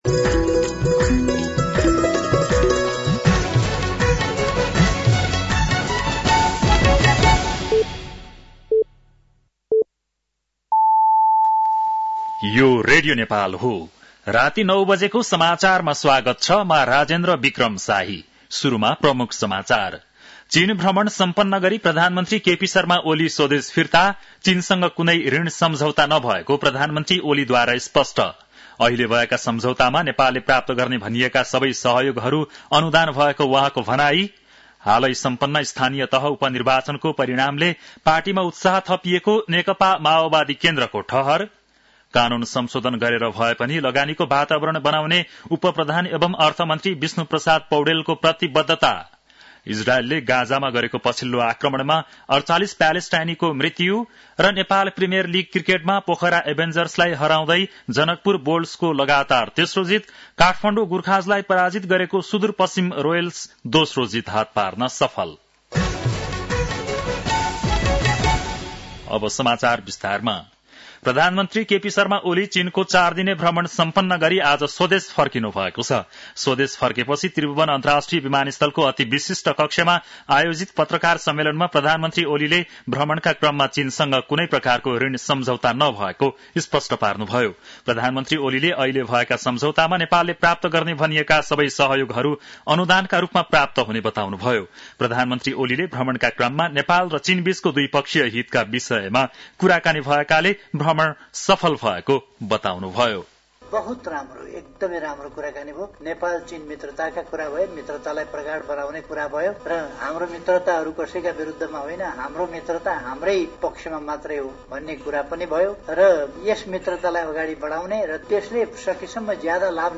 बेलुकी ९ बजेको नेपाली समाचार : २१ मंसिर , २०८१
9-PM-Nepali-NEWS-8-20.mp3